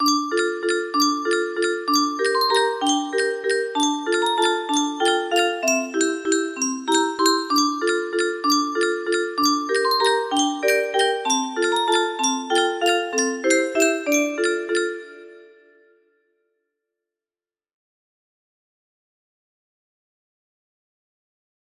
Sea Chanty music box melody